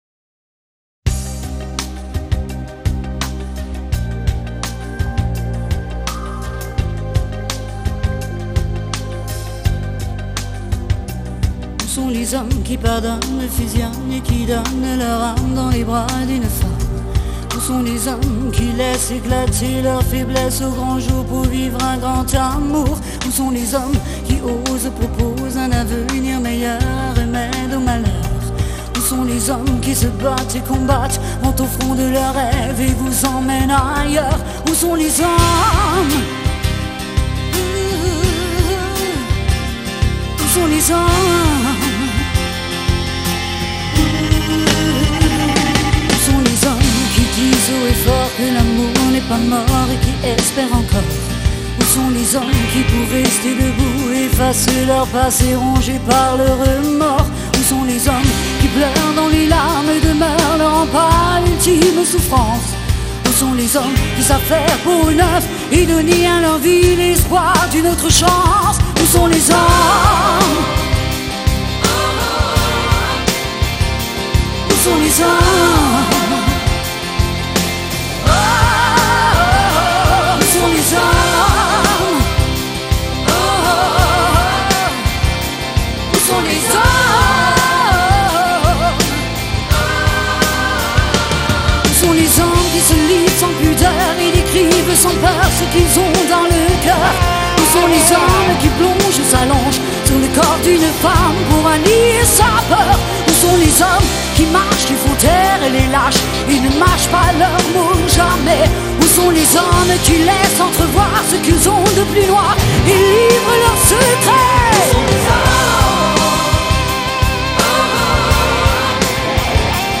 时而浑厚性感、时而缥缈清秀